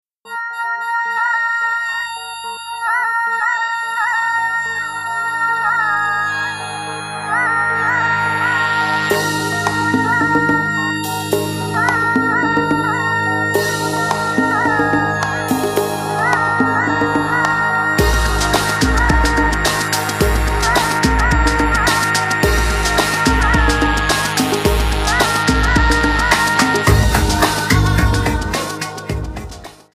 Alternative,Dance,New Age,Trance